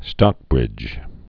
(stŏkbrĭj)